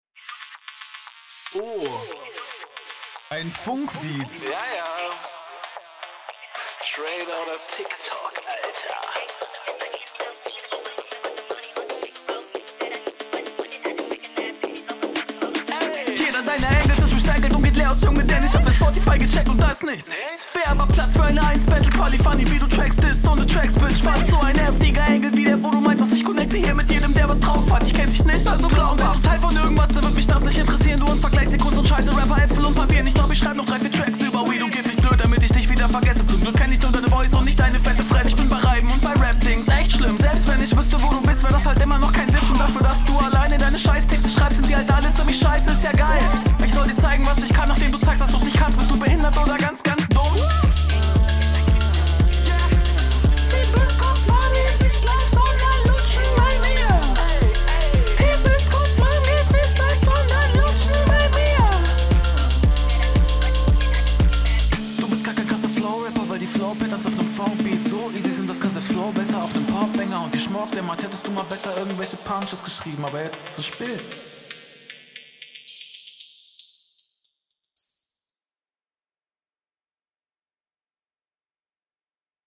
Stimmung mit dem Intro schon schön aufgebaut.